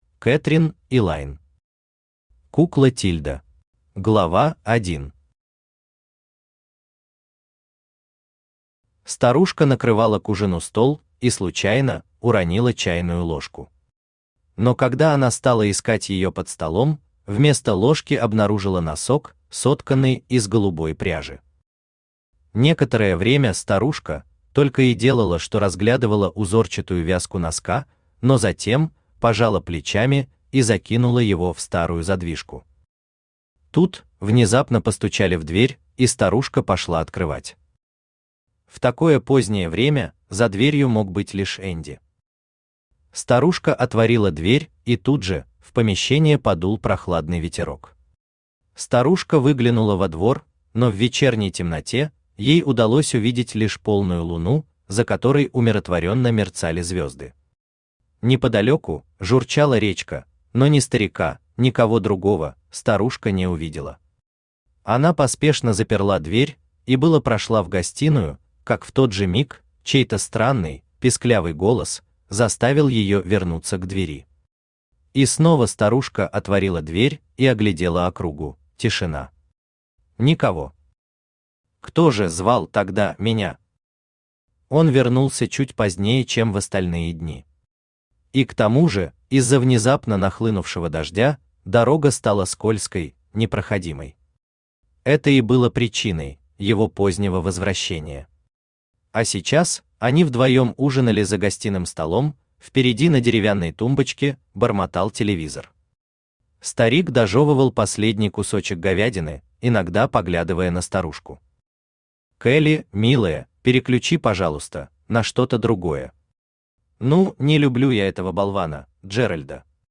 Аудиокнига Кукла Тильда | Библиотека аудиокниг